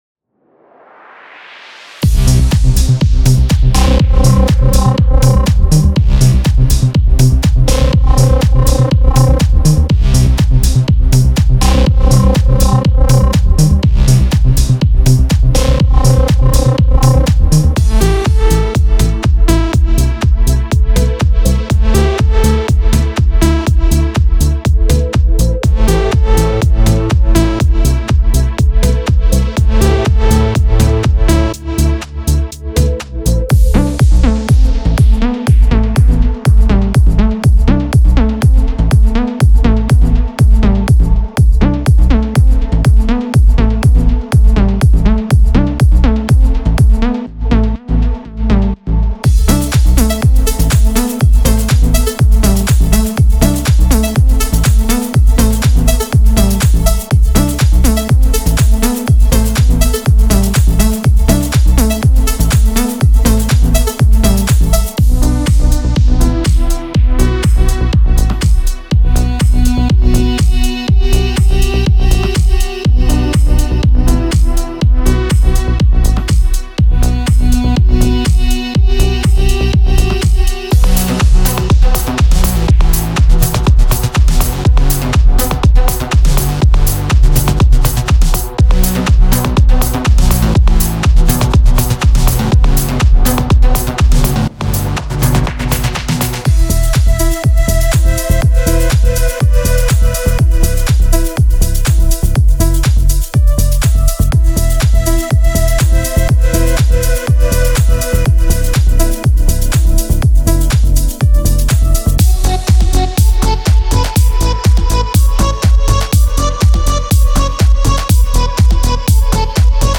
Genre:House
クラップ、ハイハット、キック、パーカッション、スネアなどが含まれており、現代のミックスに完璧にフィットします。
デモサウンドはコチラ↓